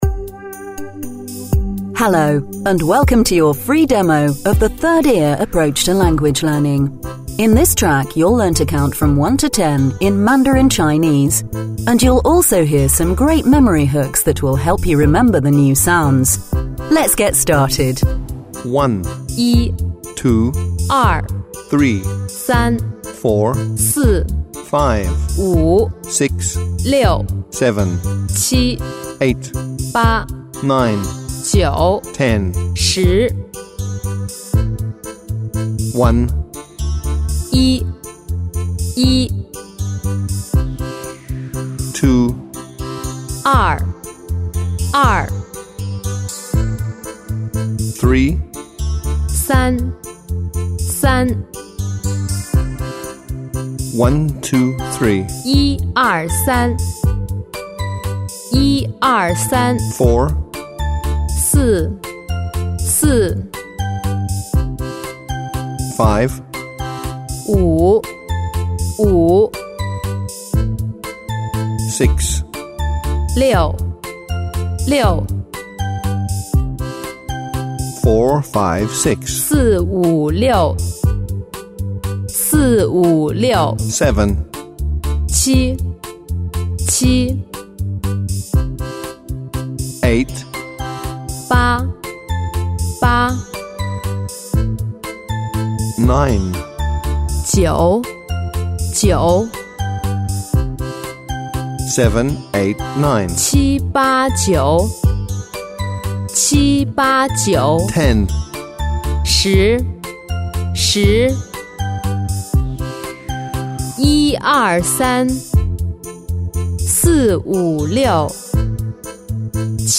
1. Rhythm and Music
2. Spoken Word Instruction and